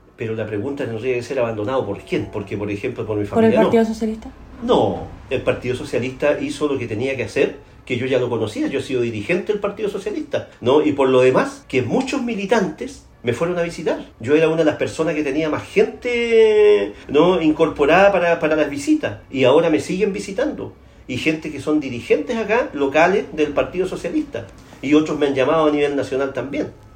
En el comedor de su casa, donde cumple la cautelar de arresto domiciliario total, con lápiz, papel y un vaso de agua, el exalcalde de Puerto Montt, Gervoy Paredes, conversó con Radio Bío Bío y defendió su inocencia afirmando que junto a su defensa tienen antecedentes que desestiman los cuatro delitos de corrupción que se le imputan al momento de liderar la capital regional de Los Lagos.